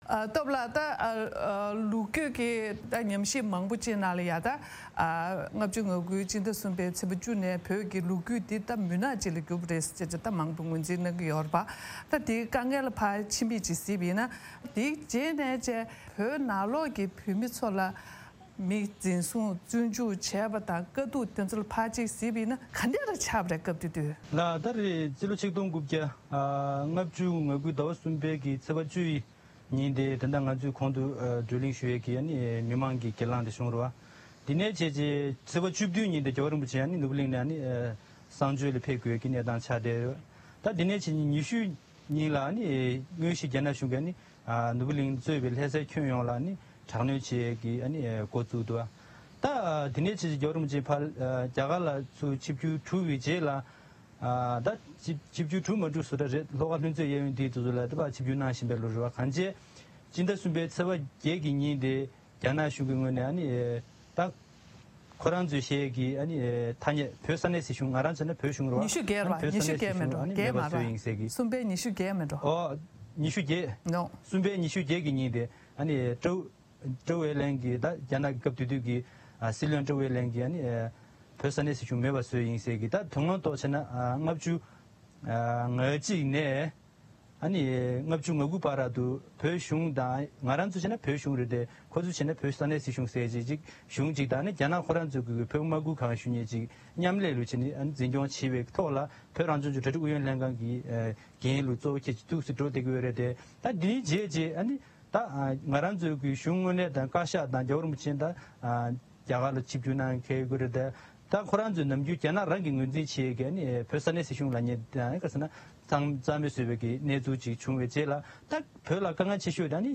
དེ་རིང་གི་ཕ་ཡུལ་གླེང་སྟེགས་ནང་རྒྱ་ནག་གིས་བོད་ལ་དབང་སྒྱུར་བྱས་ནས་ལོ་ངོ་༦༠ཕྱིན་པ་དེས་བོད་མི་རིགས་དང་རྒྱལ་རབས་ལོ་རྒྱུས་ཐོག་དུས་ཚོད་གང་འདྲ་ཞིག་མཚོན་མིན་དང་མི་ལོ་དྲུག་ཅུའི་རིང་རྒྱ་ནག་གི་ཆབ་སྲིད་ཀྱི་ལས་འགུལ་འདྲ་མིན་འོག་བོད་མིར་དཀའ་སྡུག་དང་། ཡང་དྲག་གནོན་འོག་མི་རིགས་ཀྱི་ངོ་བོ་སྲུང་སྐྱོབ་ཐད་གཞིས་ལུས་བོད་མིའི་སེམས་ཤུགས་སོགས་༦༠རིང་བོད་ནང་གི་གནས་སྟངས་ཐད་ལ་བགྲོ་གླེང་ཞུས་པ་ཞིག་རྒྱང་སྲིང་ཞུ་རྒྱུ་ཡིན།